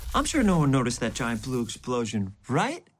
Giant Blue Explosion Sound Effect Free Download
Giant Blue Explosion